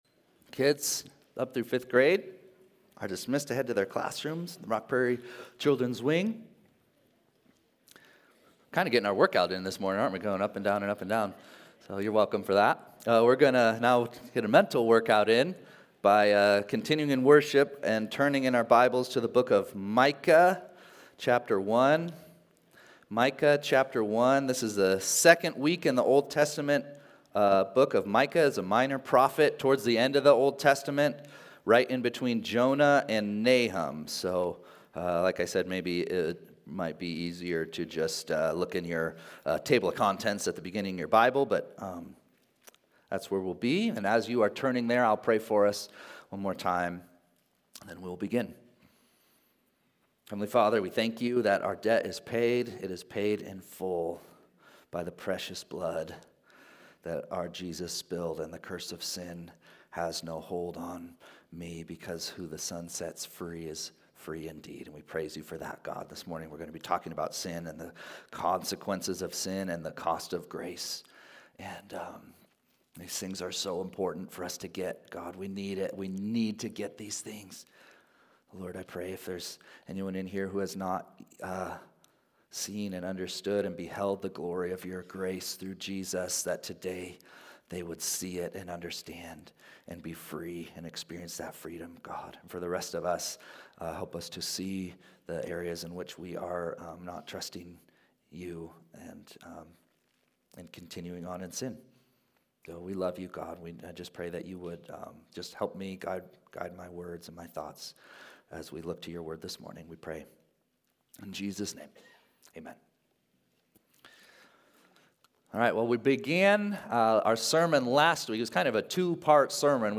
11-4-24-Sunday-Service.mp3